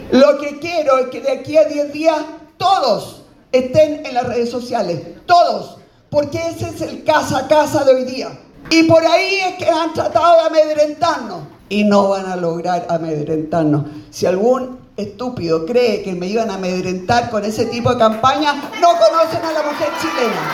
En un encuentro con adherentes en Valdivia este sábado, los llamó a utilizar activamente las redes sociales, asegurando que es el nuevo puerta a puerta.